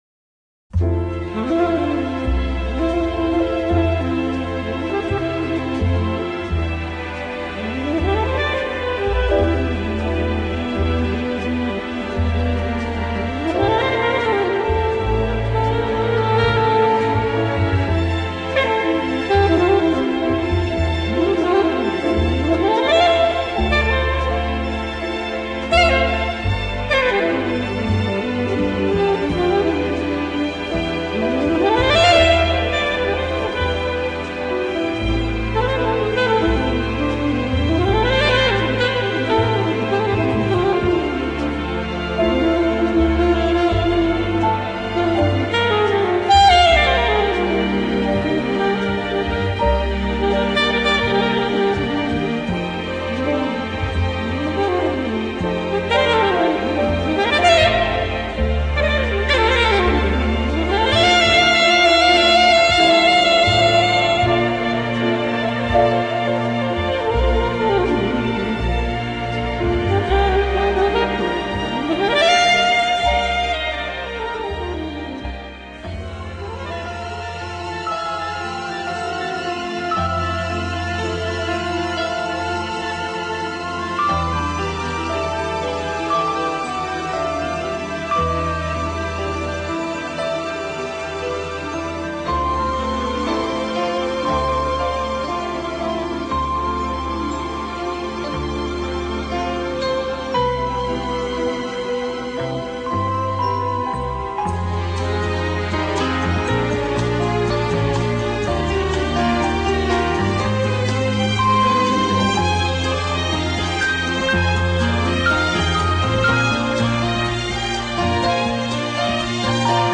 Générique ram